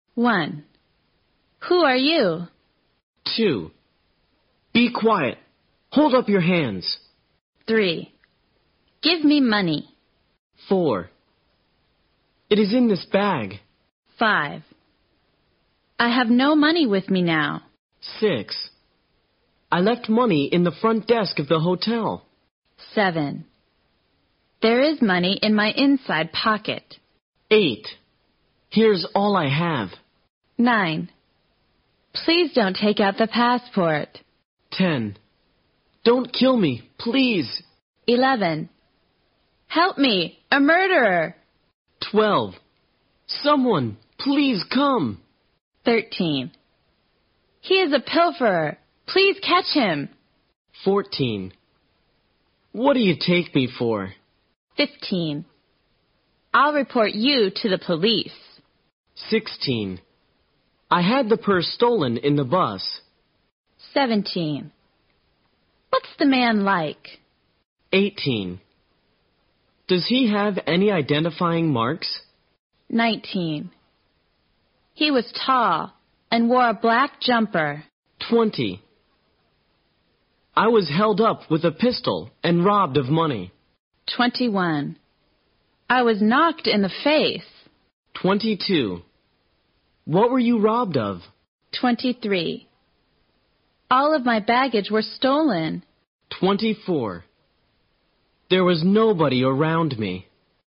在线英语听力室随身应急英语会话 第19期:在城里的紧急情况(7)的听力文件下载, 《随身应急英语会话》包含中英字幕以及地道的英语发音音频文件，是学习英语口语，练习英语听力，培养提高英语口语对话交际能力的好材料。